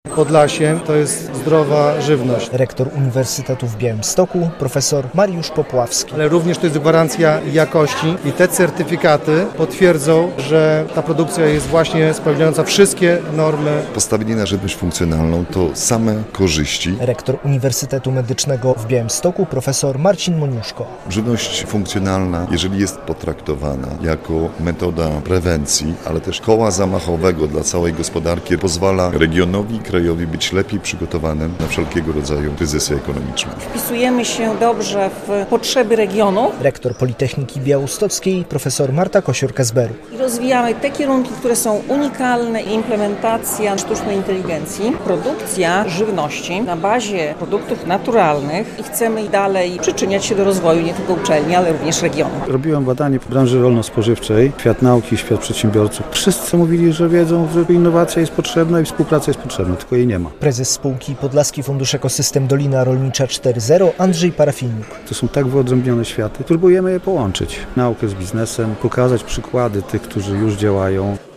W Białymstoku trwa konferencja "4Podlaskie - Na zdrowie!" inaugurująca przedsięwzięcie Podlaski Fundusz "Ekosystem Dolina Rolnicza 4.0".
relacja